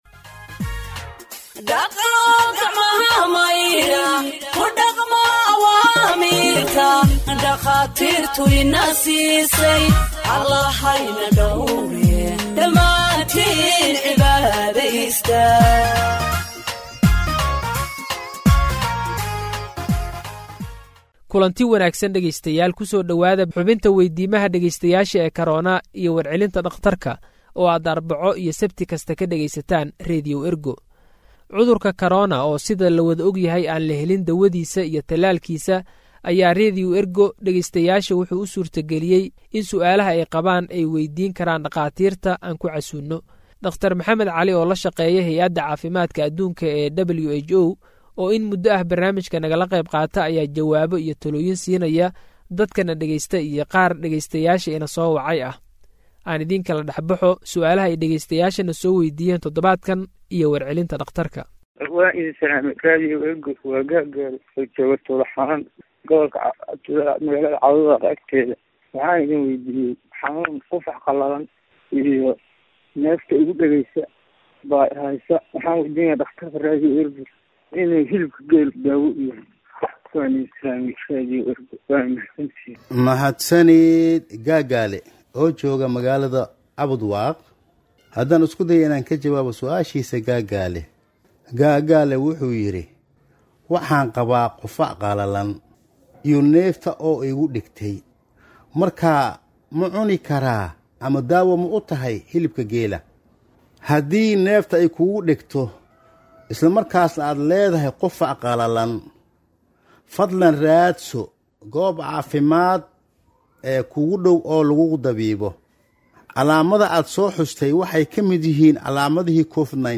Health expert answers listeners’ questions on COVID 19 (32)